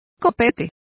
Complete with pronunciation of the translation of quiff.